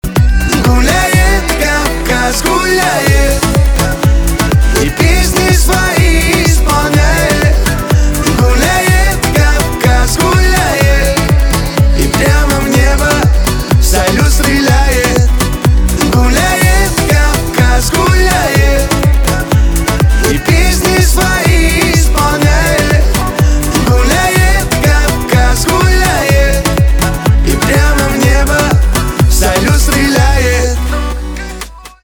поп
кавказские , праздничные , битовые , кайфовые